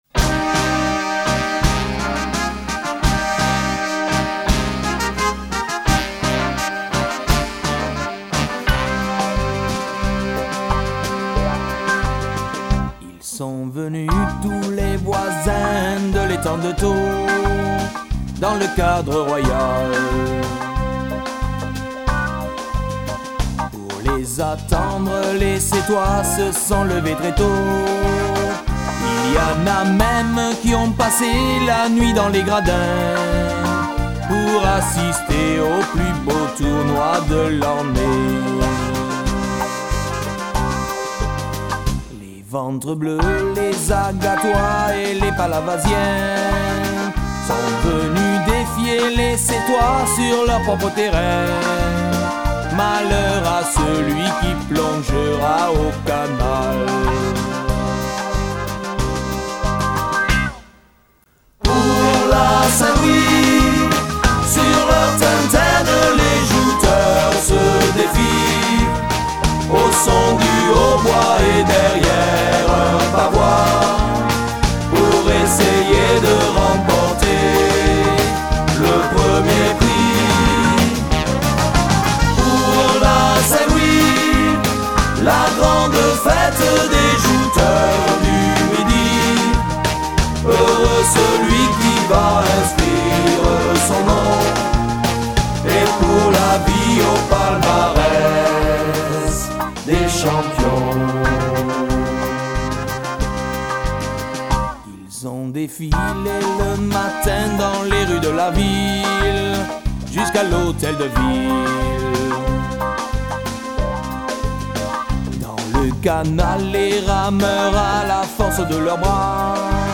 Genre strophique
Pièce musicale éditée